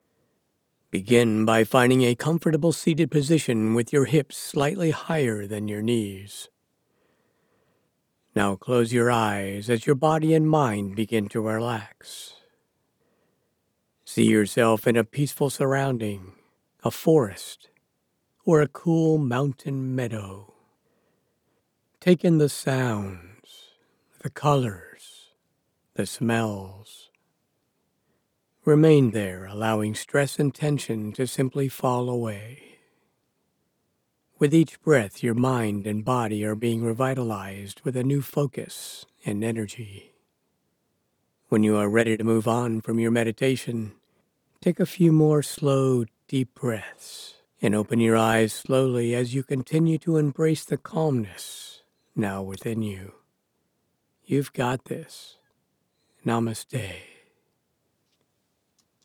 Narration Meditation App
Narration Meditation App.mp3